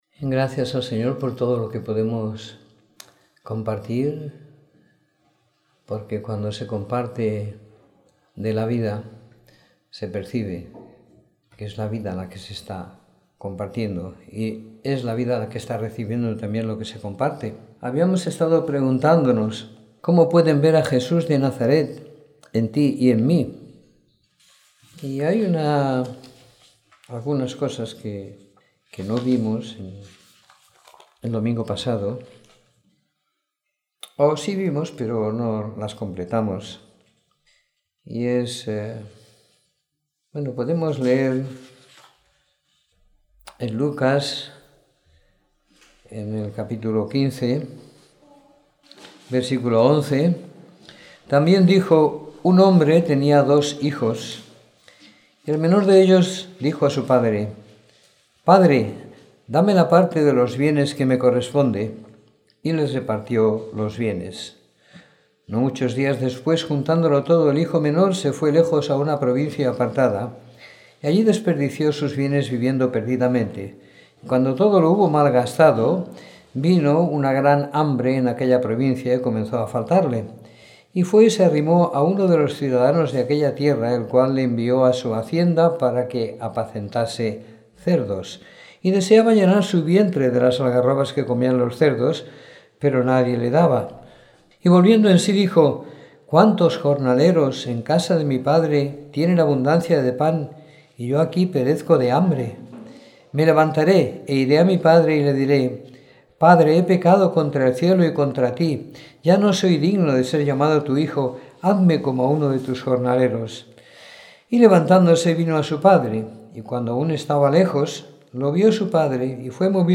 Domingo por la Tarde . 19 de Junio de 2016